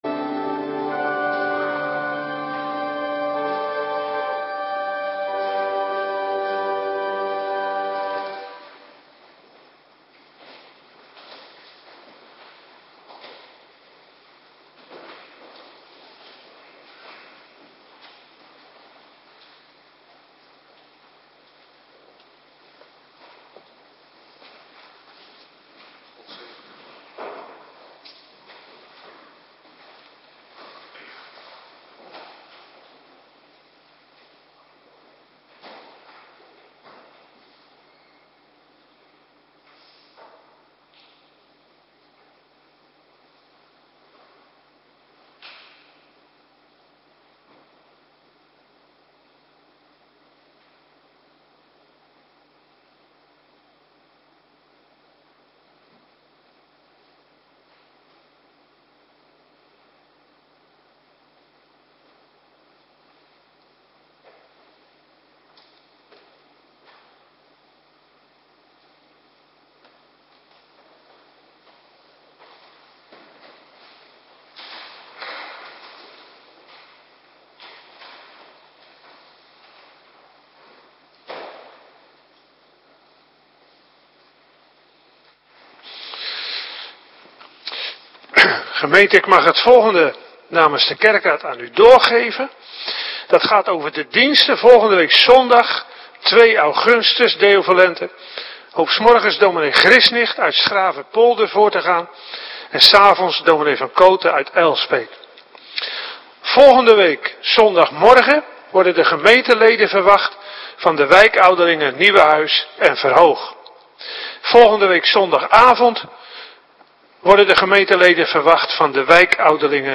Avonddienst - Cluster 2